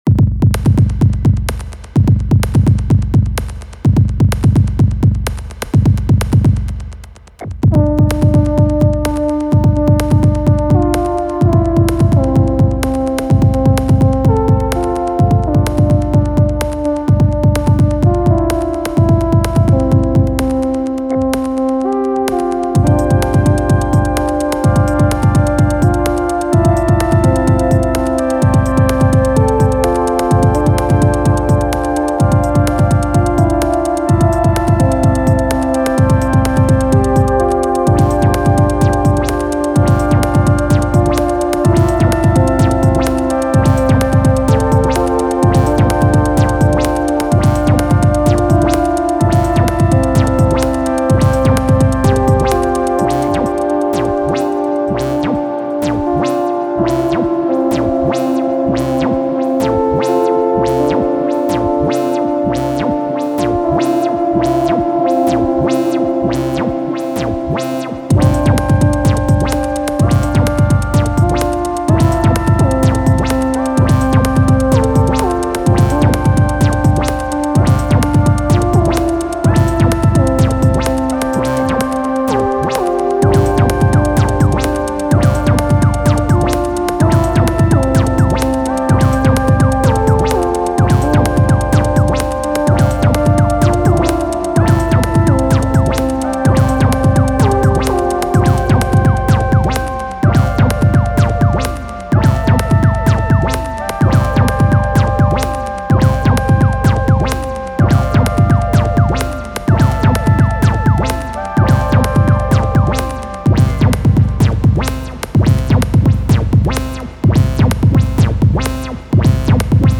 late night with DTII+Nymphes